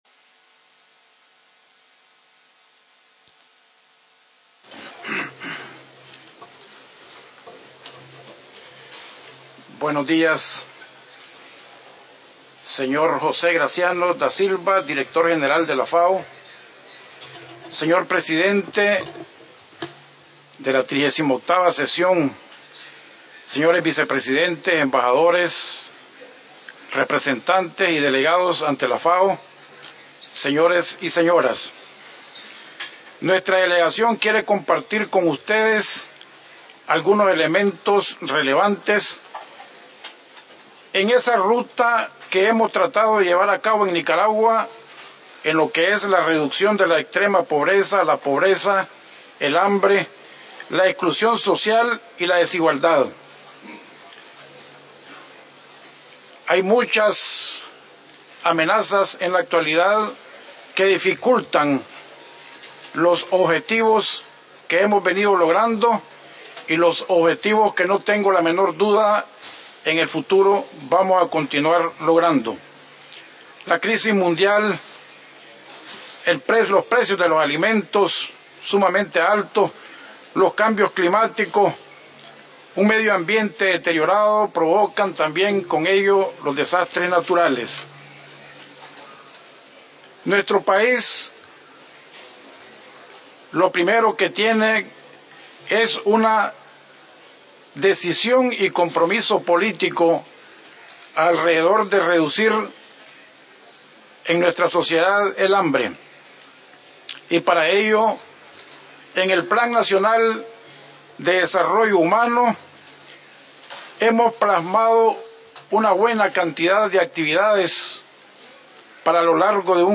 FAO Conference
Statements by Heads of Delegations under Item 9:
Excmo. Sr. Don Omar Halleslevens Acevedo Vice Presidente de la República de Nicaragua